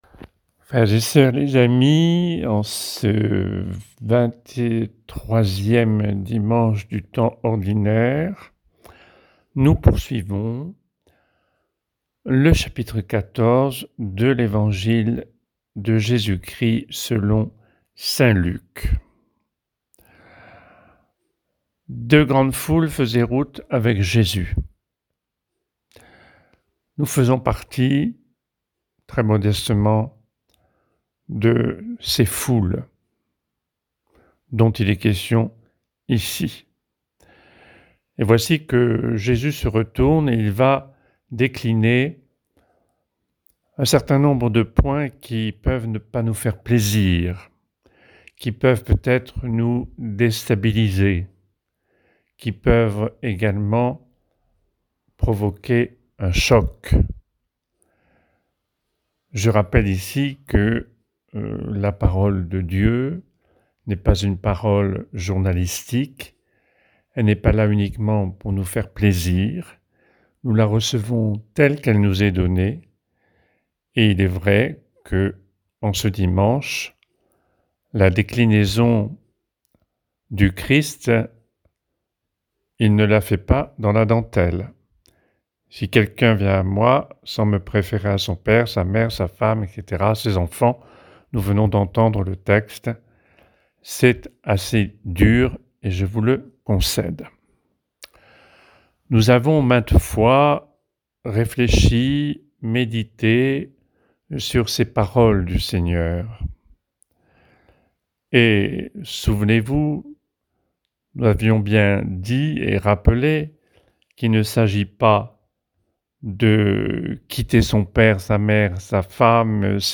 Méditation
7 septembre 2025 – 23ème dimanche du temps ordinaire